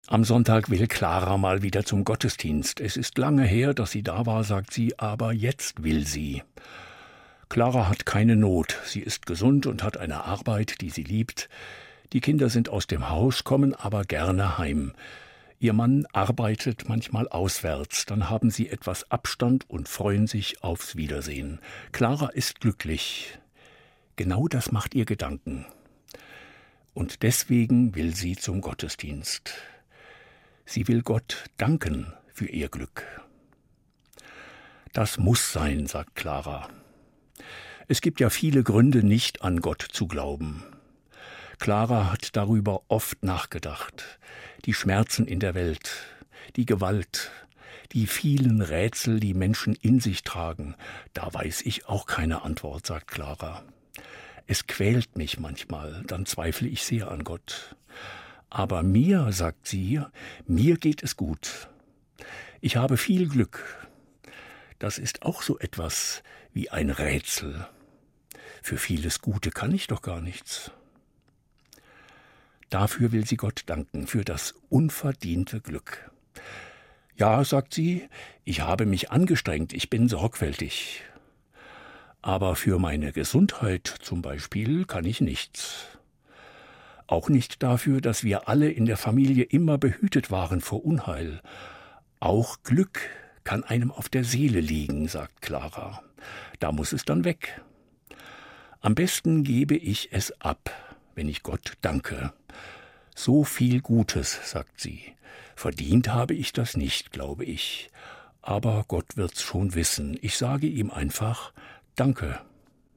Evangelischer Pfarrer, Kassel